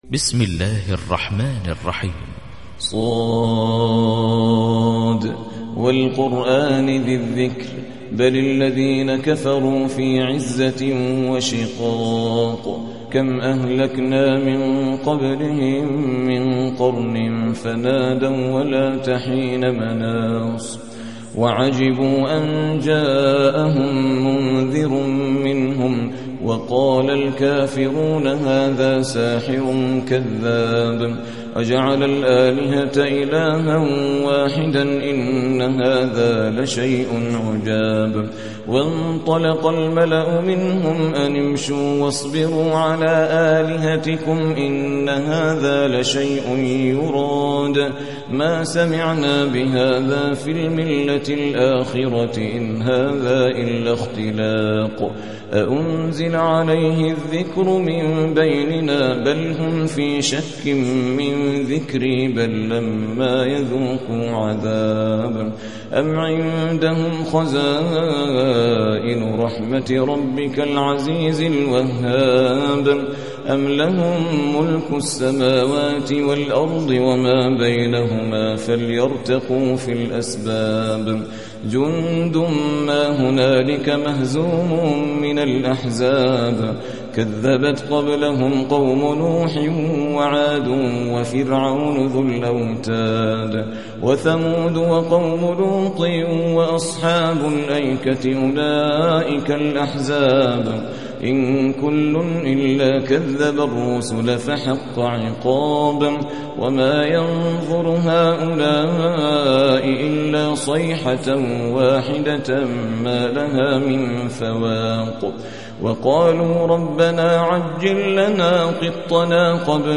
38. سورة ص / القارئ